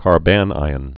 (kär-bănīən, -īŏn)